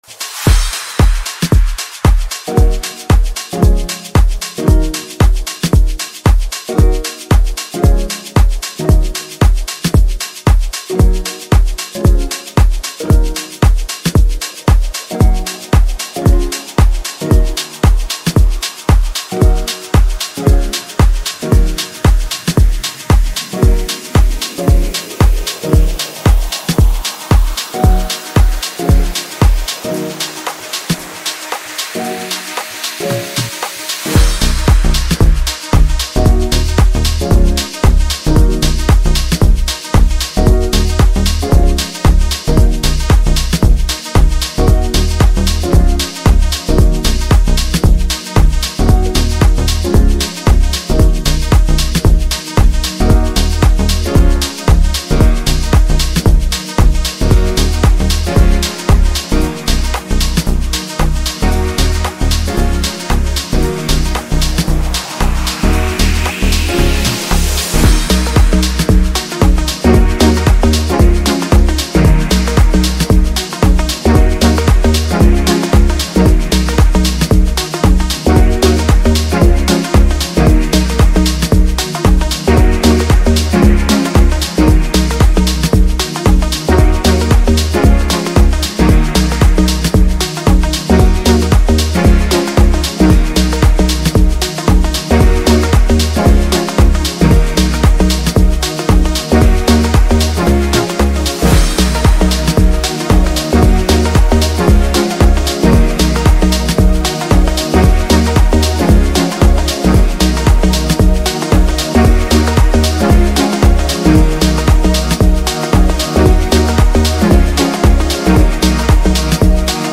Exceptionally talented singer